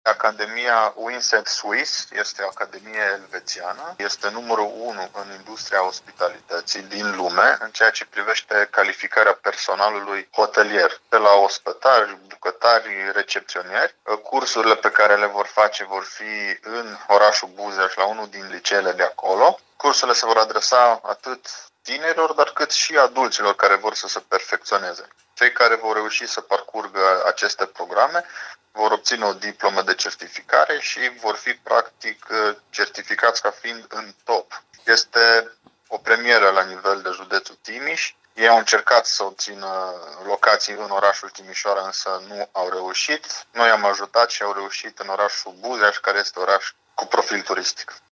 Vicepreședintele Consiliului Județean Timiș, Alexandru Proteasa